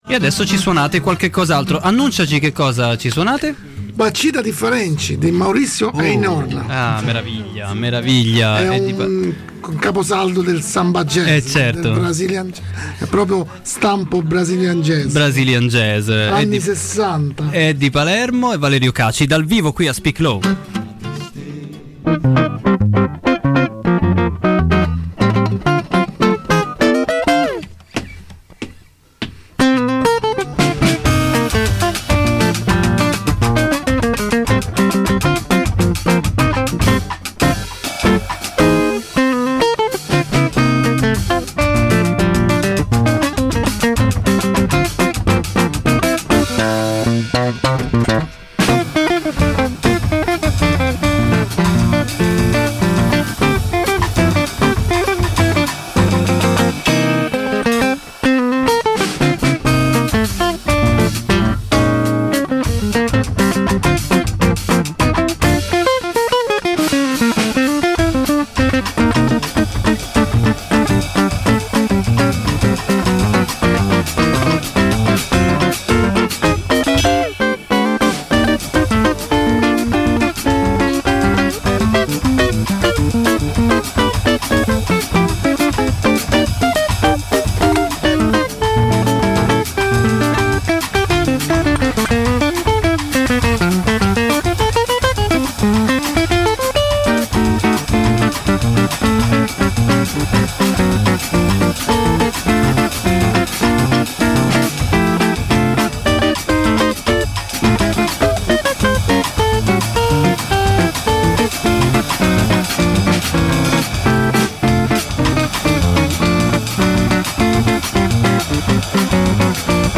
suonano dal vivo